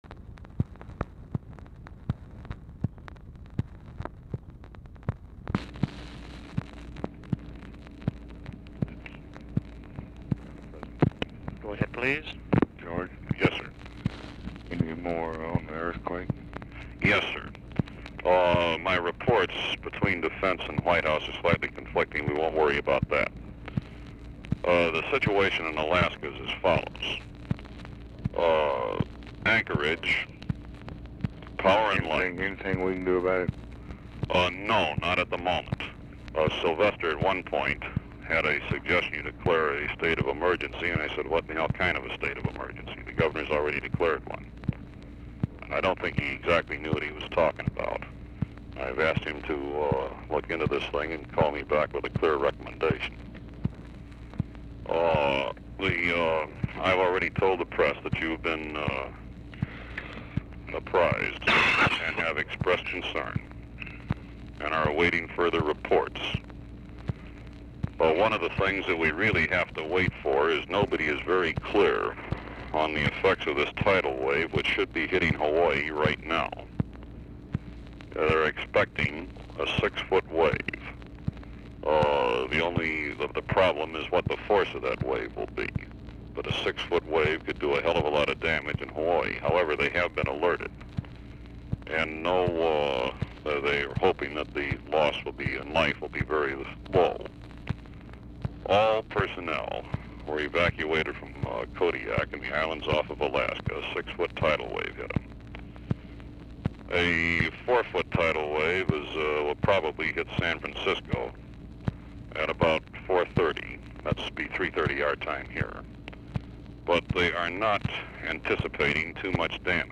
Telephone conversation # 2681, sound recording, LBJ and GEORGE REEDY, 3/28/1964, 3:06AM | Discover LBJ
Format Dictation belt
Location Of Speaker 1 LBJ Ranch, near Stonewall, Texas
Specific Item Type Telephone conversation